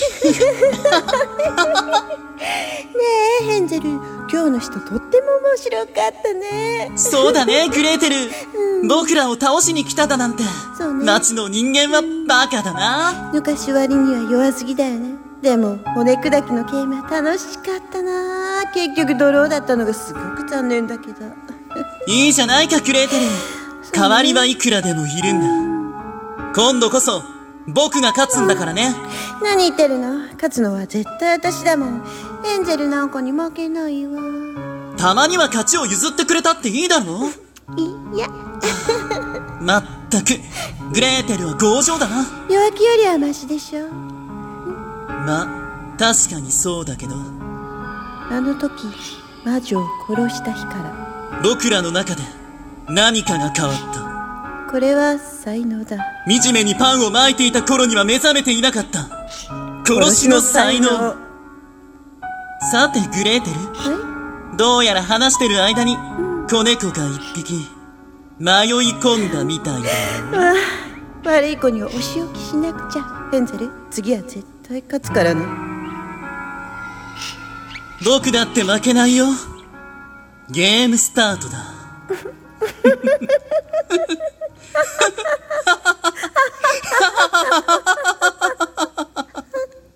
ヘンゼルとグレーテル【声劇】